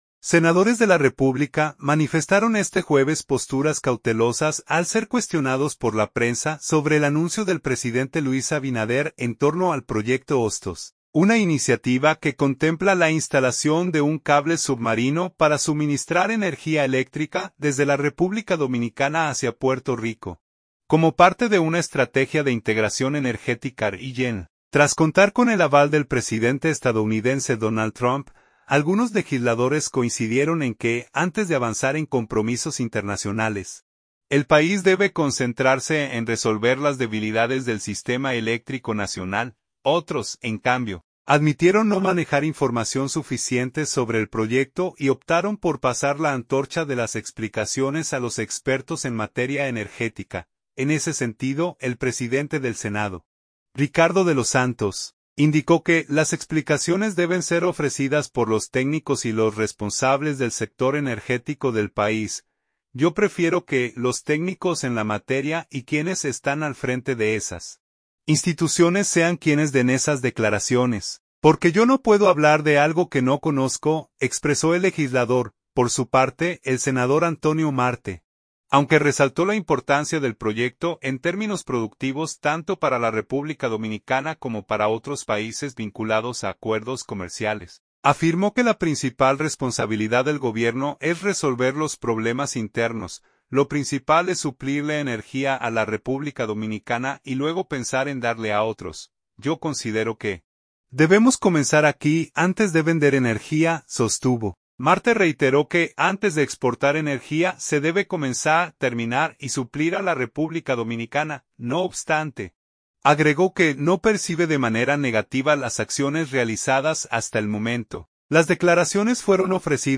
Las declaraciones fueron ofrecidas por los legisladores al ser abordados por la prensa a la salida de la sesión ordinaria celebrada en la Cámara Alta del Congreso Nacional.